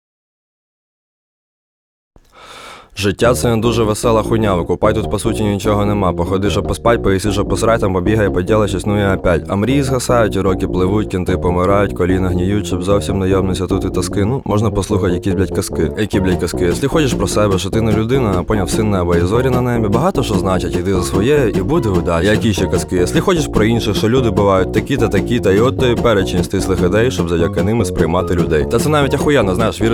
Жанр: Рэп и хип-хоп / Русские
# Hip-Hop